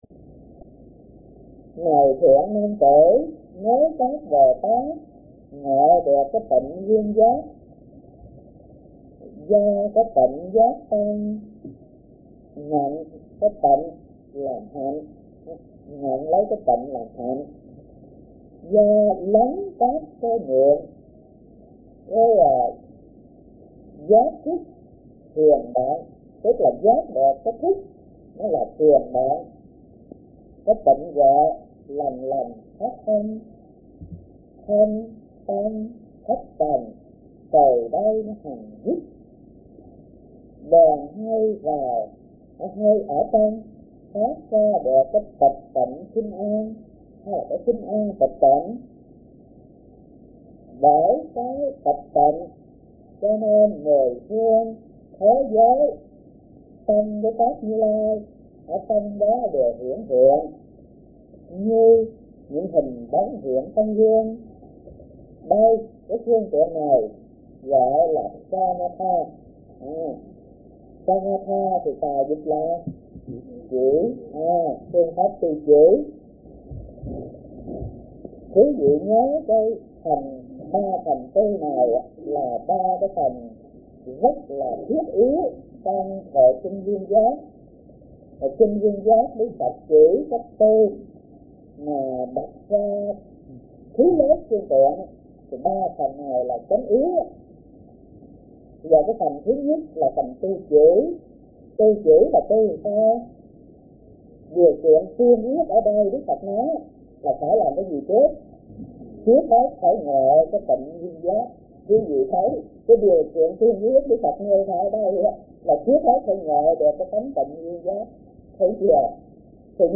Kinh Giảng Kinh Viên Giác - Thích Thanh Từ